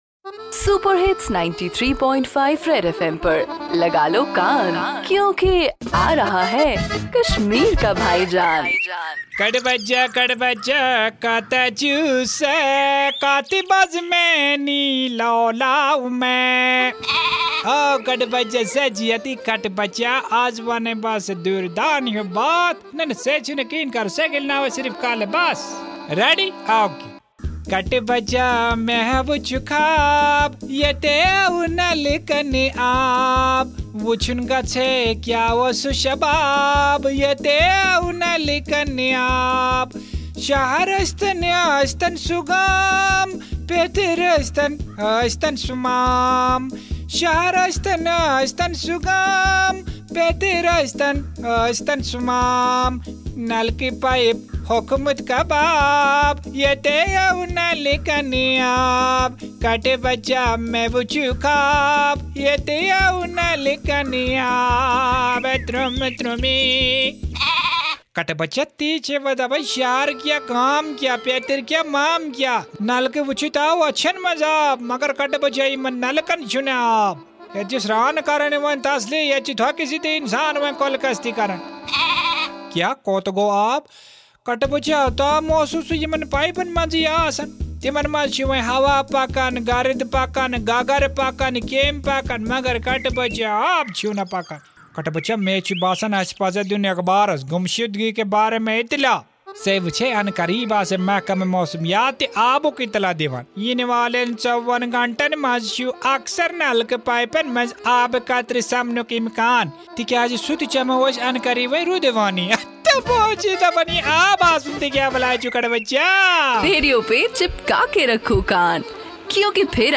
Bhaijaan the ultimate dose of comedy in Kashmir which is high on satire and humor